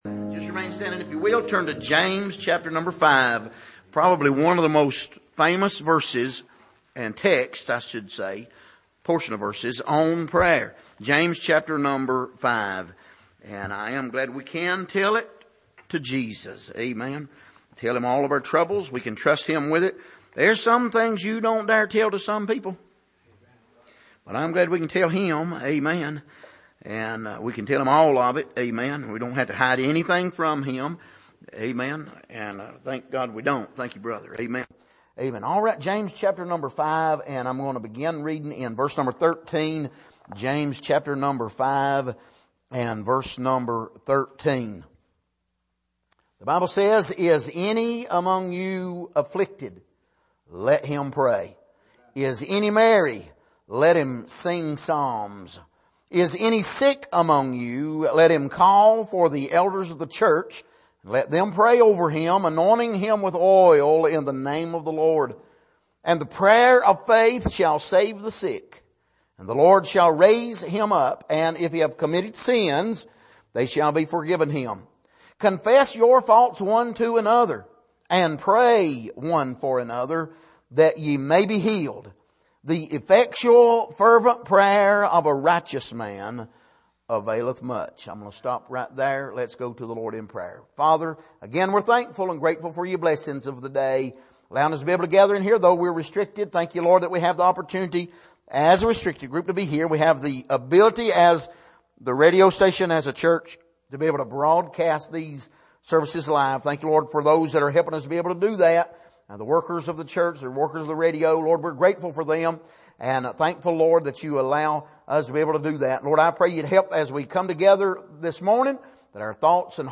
Passage: James 5:13-16 Service: Sunday Morning Pray One For Another « No Condemnation in Christ What is Biblical Faith?